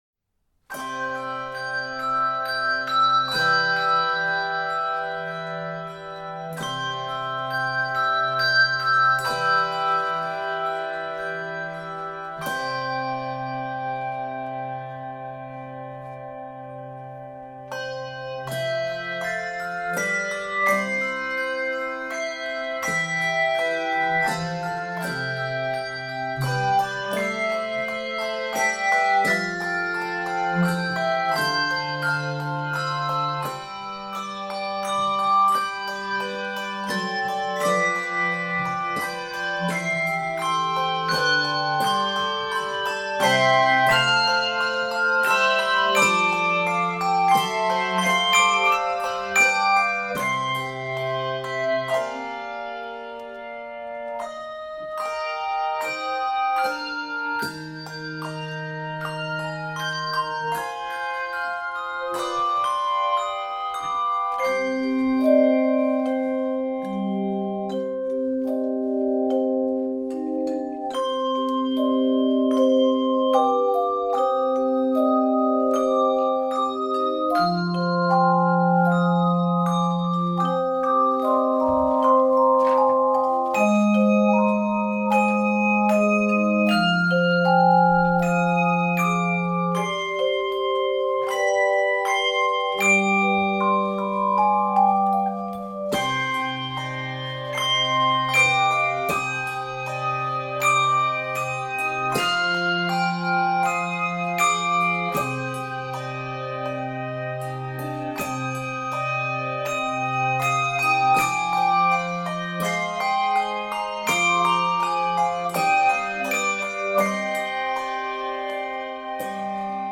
this tender arrangement
Keys of C Major and c minor.